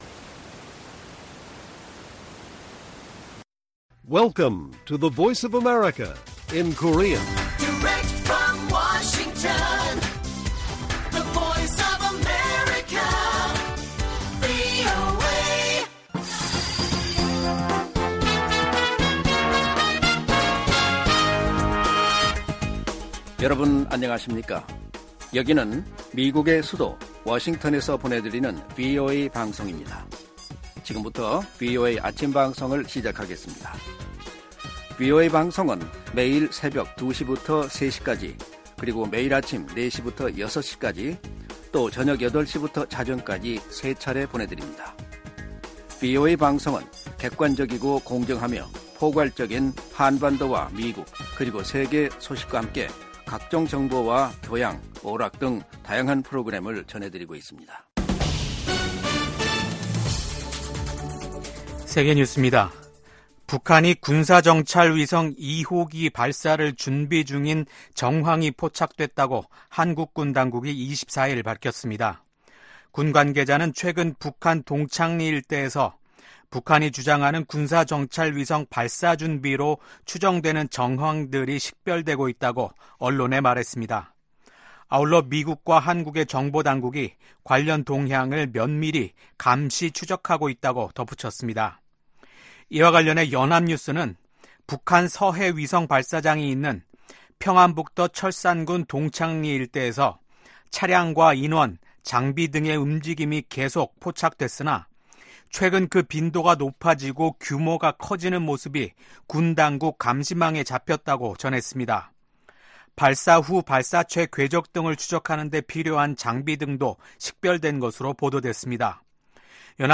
세계 뉴스와 함께 미국의 모든 것을 소개하는 '생방송 여기는 워싱턴입니다', 2024년 5월 25일 아침 방송입니다. '지구촌 오늘'에서는 국제사법재판소(ICJ)가 24일 이스라엘군의 라파 공격을 중단시켜 달라는 요청에 대한 결정을 내릴 예정에 대한 소식 전해드리고, '아메리카 나우'에서는 미 연방대법원이 인종 편향성 논란이 인 사우스캐롤라이나주 선거구 획정을 허용하는 판결을 내린 이야기 살펴보겠습니다.